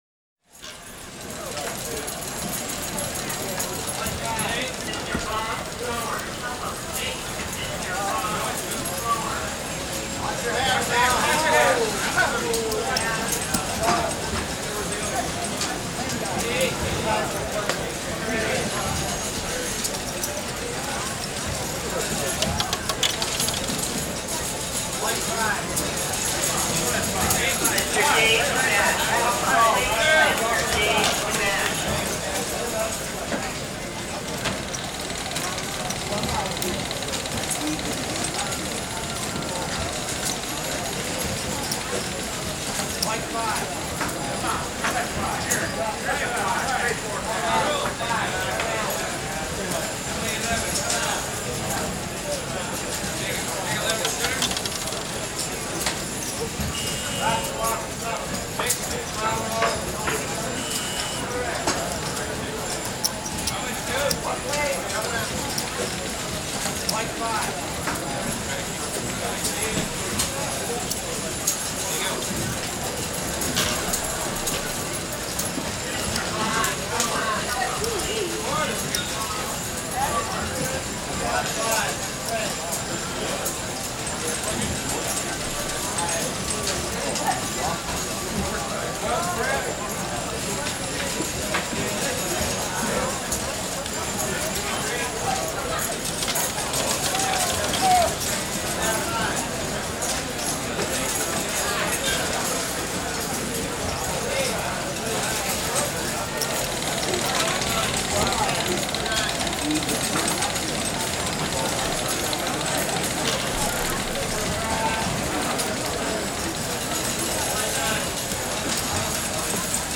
Casino Sound
ambience
Casino